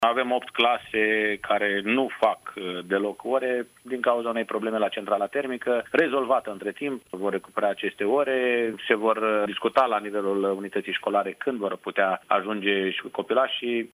În restul județului Suceava, toate cursurile au început, astăzi, în mod normal, după cum a declarat, pentru postul nostru de radio, șeful Inspectoratului Școlar Județean, Ciprian Anton: ”Avem 8 clase care nu fac deloc ore din cauza unei probleme la centrala termică, rezolvată între timp. Vor recuperea aceste ore, se vor discuta la nivelul unității școlare când vor putea ajunge și copilașii.”